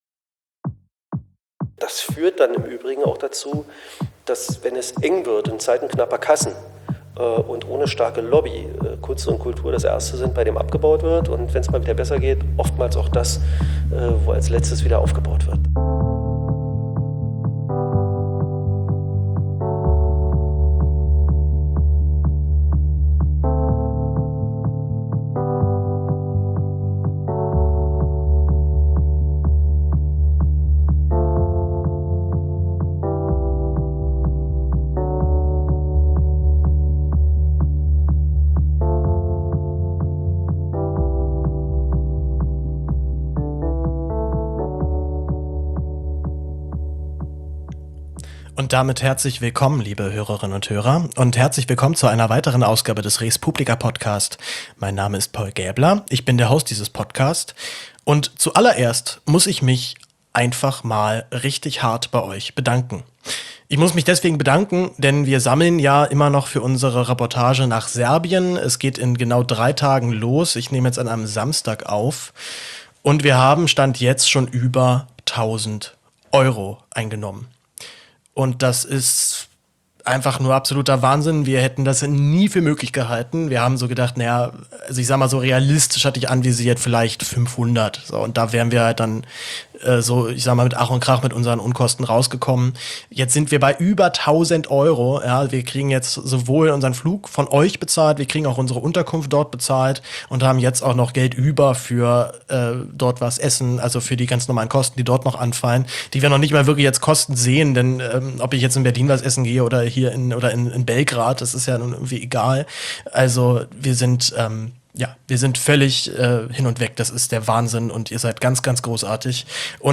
Ich kläre über den letzten Stand auf und bedanke mich ausführlichst bei meinen vielen großartigen Unterstützer:innen. Danach rede ich eine ganze Stunde mit Klaus Lederer, Senator für Kultur und Europa in Berlin, über seine Arbeit, den Elfenbeinturm der Kultureliten und wie eine vernünftigte Drogenpolitik aussehen kann.